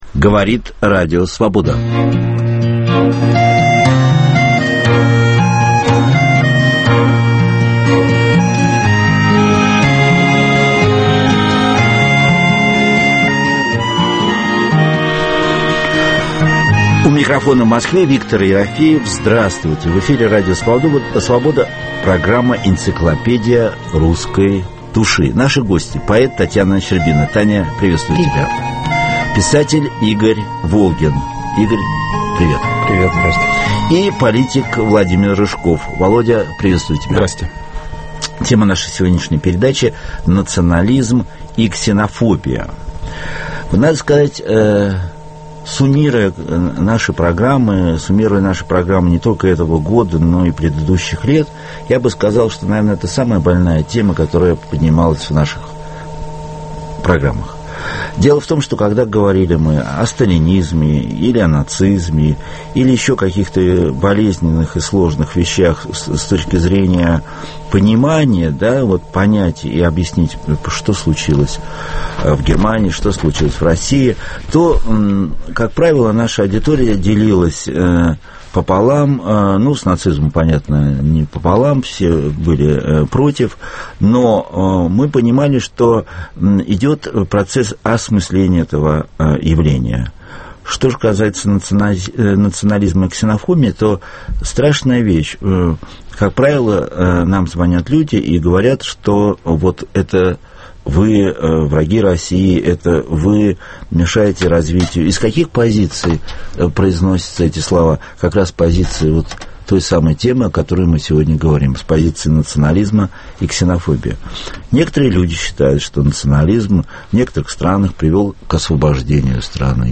Гости - писатель Игорь Волгин, правозащитник Серей Ковалев, политик Владимир Рыжков. Тема - "Национализм и ксенофобия".